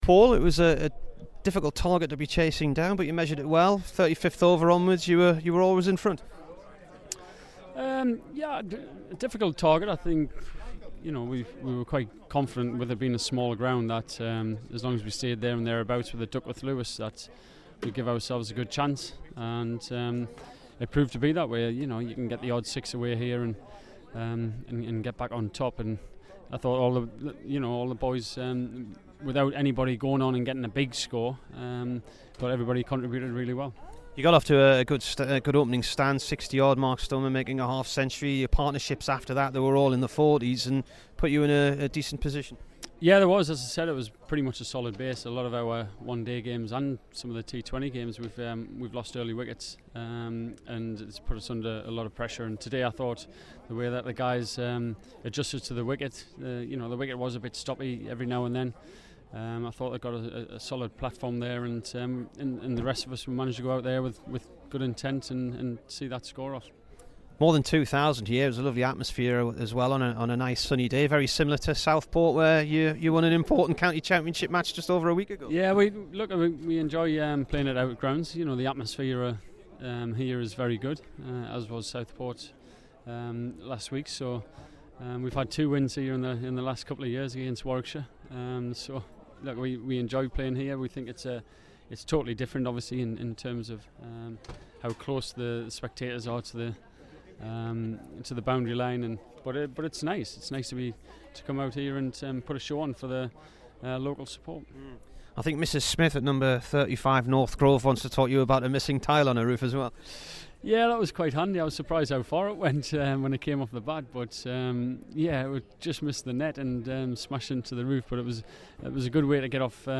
HERE'S THE DURHAM CAPTAIN AFTER THE ONE DAY CUP WIN V WARWICKSHIRE AND THE NEWS OF STONEMAN AND MUSTARD'S DEPARTURES.